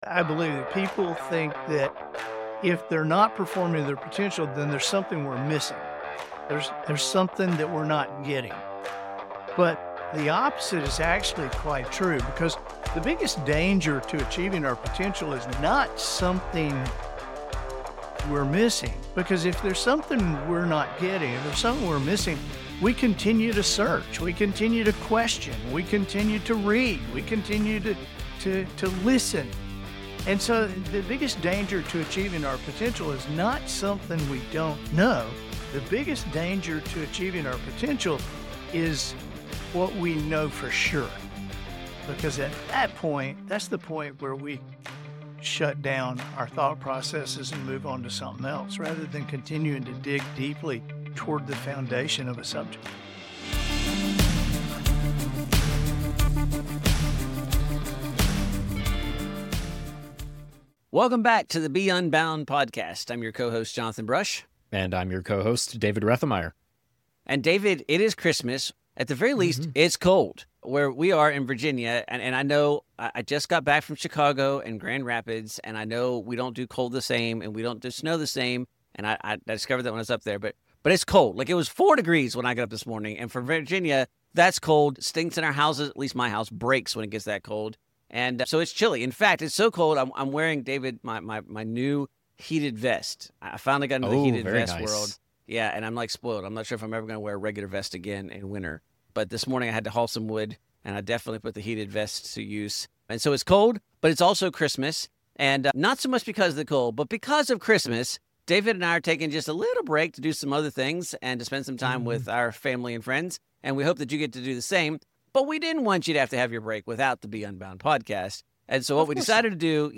This episode features bestselling author and speaker Andy Andrews—someone whose thinking has deeply shaped both of us and the way we approach teaching at Unbound.